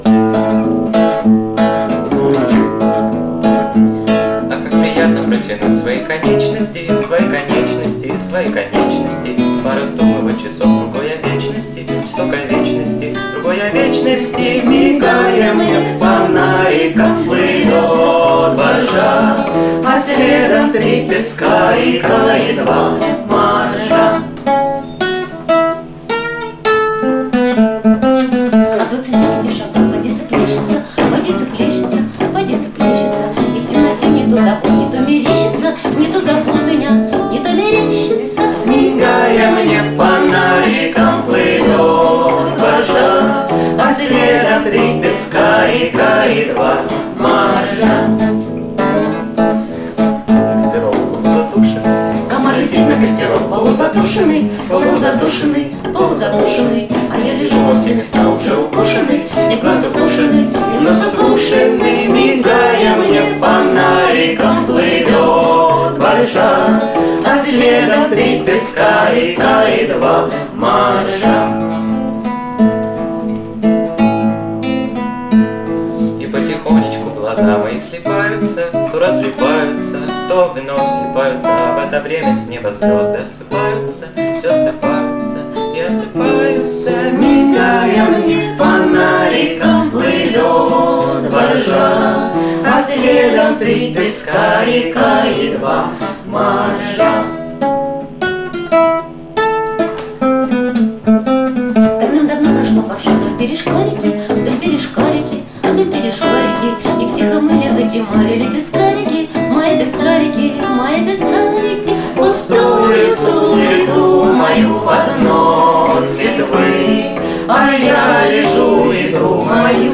31.05.2024. Творческий отчёт ансамбля